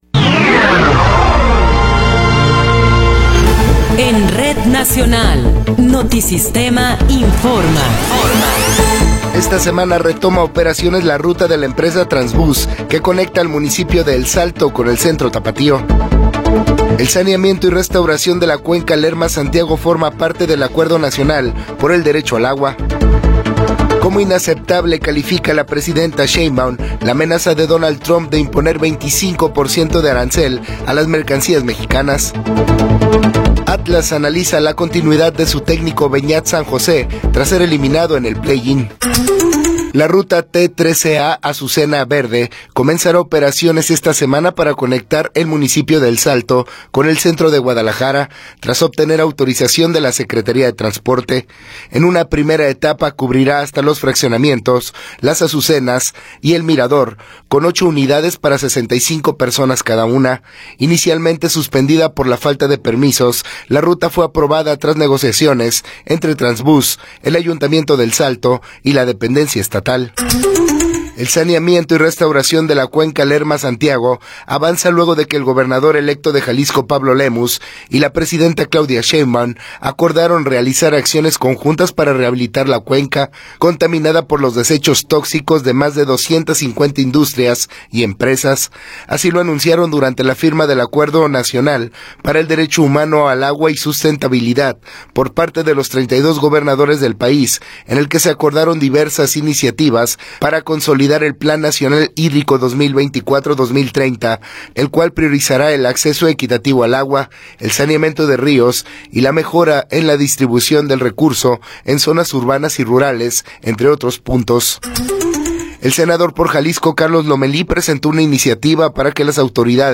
Noticiero 9 hrs. – 26 de Noviembre de 2024
Resumen informativo Notisistema, la mejor y más completa información cada hora en la hora.